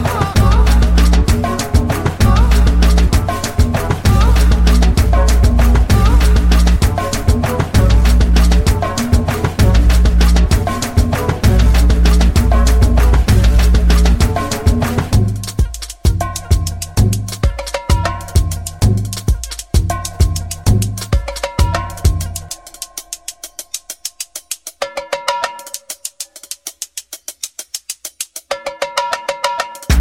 Retrofuturismo cerrado
Atmosfera arejada